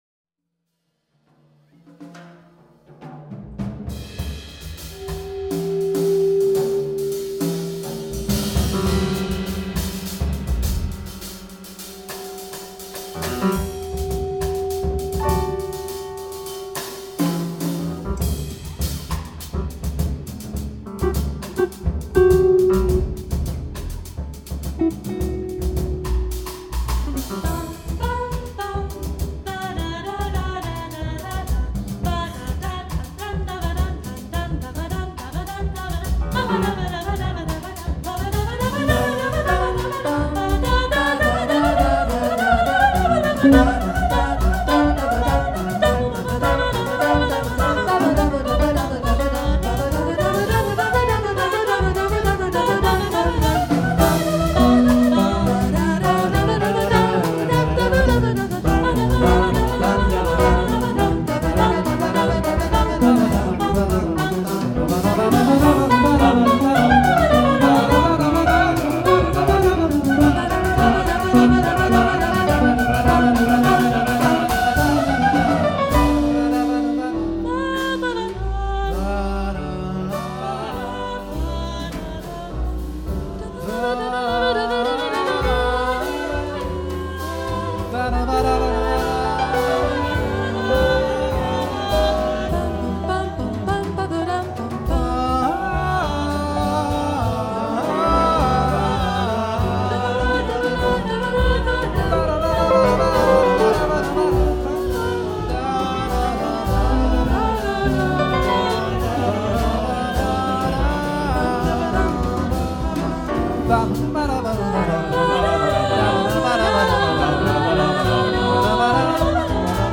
SATB, rhythm